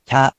In romaji, 「ひゃ」 is transliterated as「hya」which sounds like「hyahh」.
Rather than the incorrect ‘✖hee-yah’, it is pronounced as simply ‘〇hyah’.